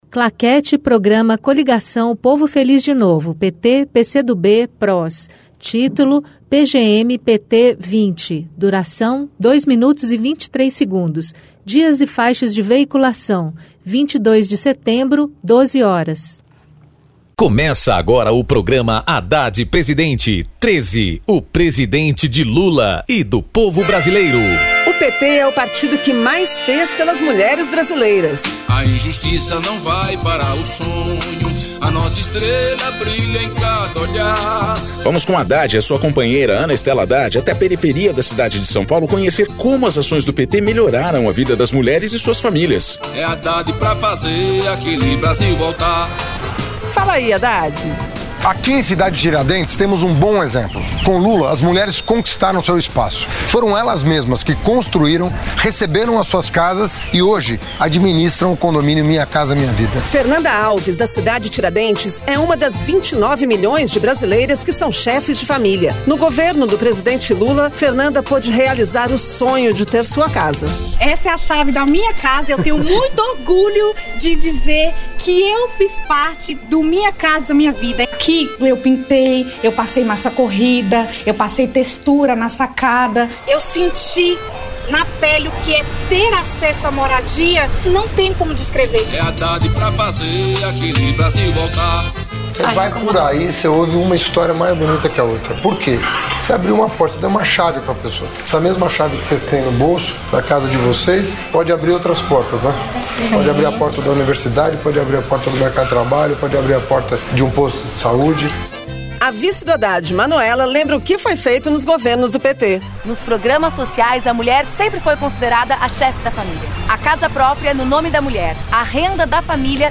Gênero documentaldocumento sonoro
Descrição Programa de rádio da campanha de 2018 (edição 20) - 1° turno